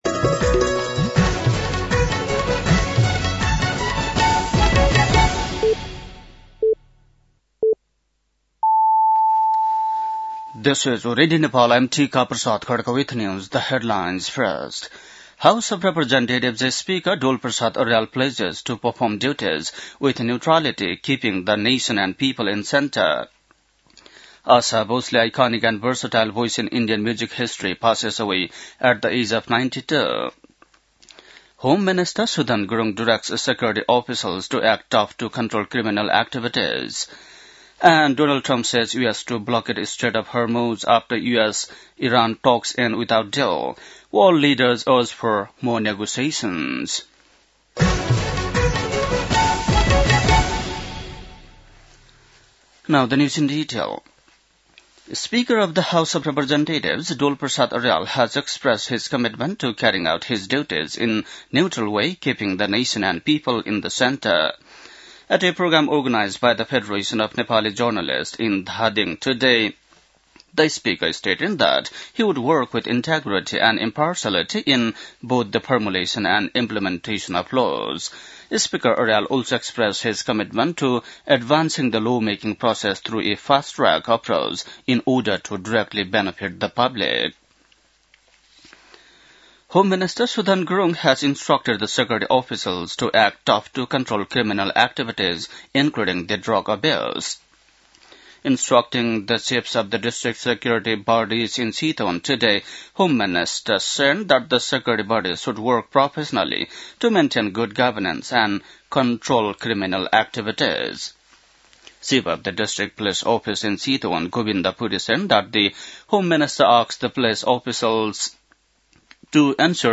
बेलुकी ८ बजेको अङ्ग्रेजी समाचार : २९ चैत , २०८२
8.-pm-english-news-1-1.mp3